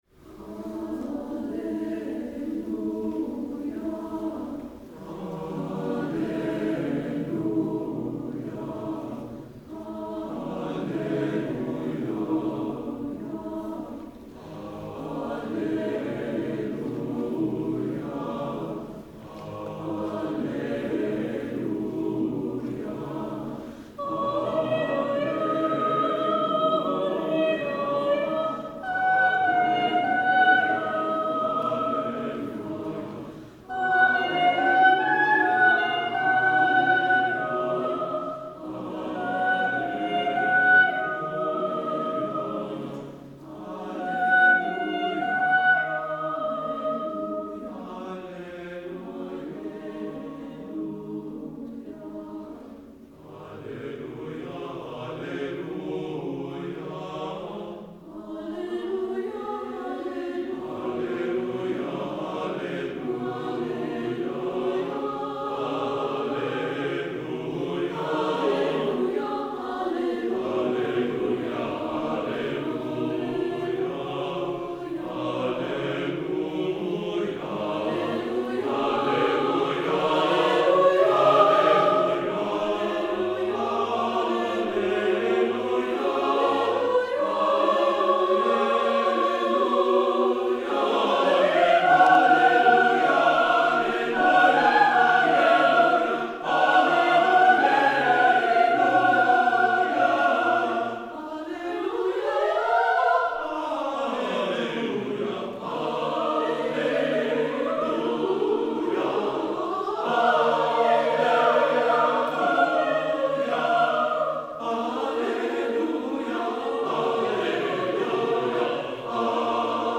Instrumentation: SATB with brief soprano solo
Grace Lutheran Church, Chicago, IL